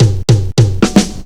FILL 4    -R.wav